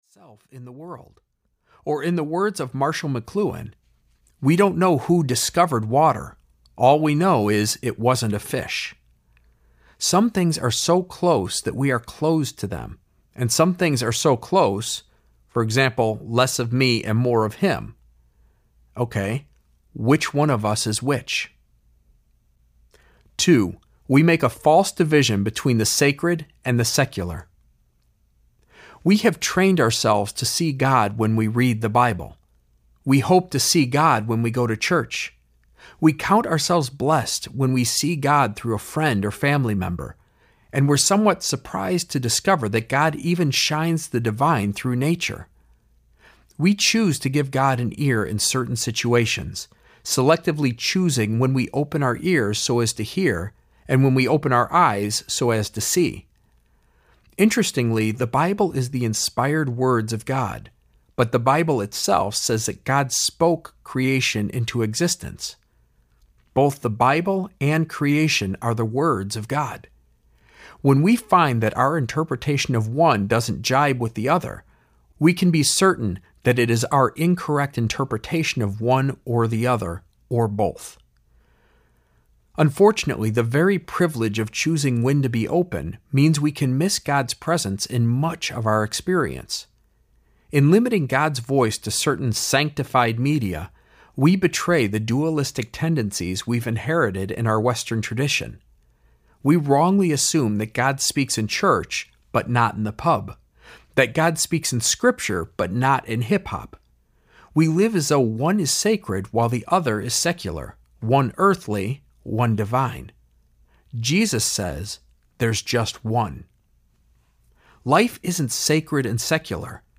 Nudge Audiobook
Narrator
9.25 Hrs. – Unabridged